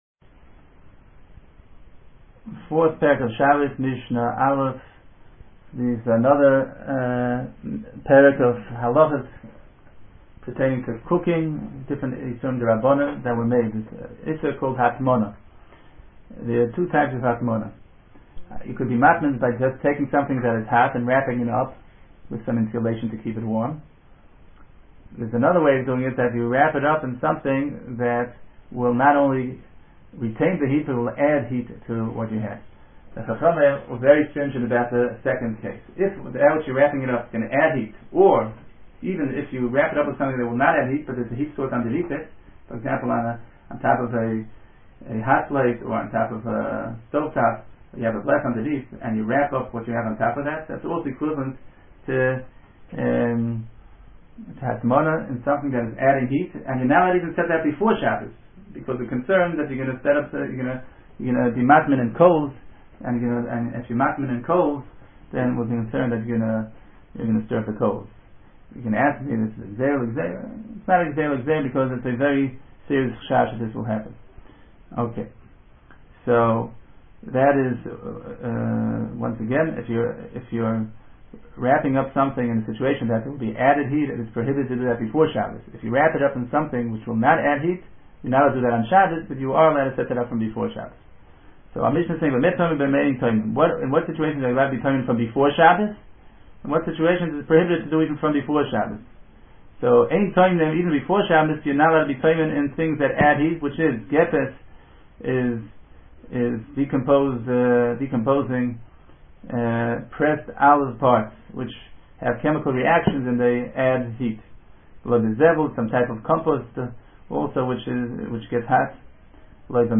Hear the Mishnah and its Halachos